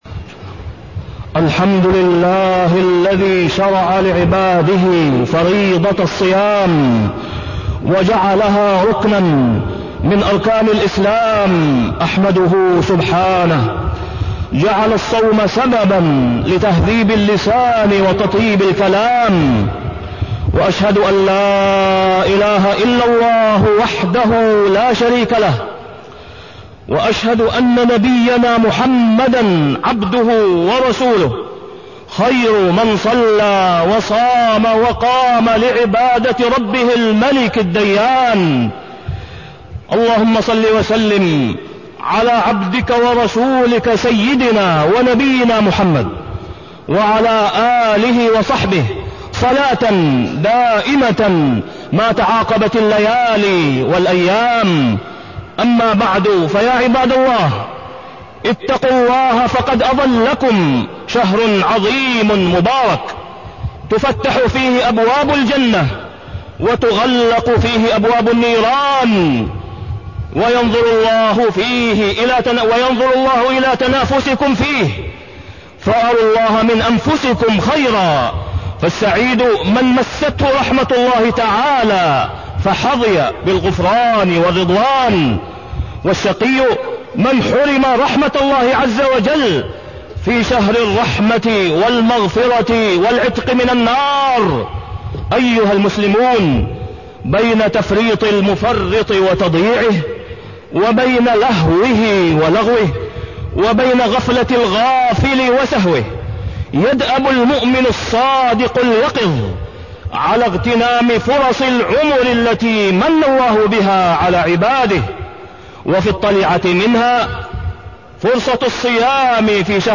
تاريخ النشر ١٠ رمضان ١٤٢٣ هـ المكان: المسجد الحرام الشيخ: فضيلة الشيخ د. أسامة بن عبدالله خياط فضيلة الشيخ د. أسامة بن عبدالله خياط منافع الصيام وآثاره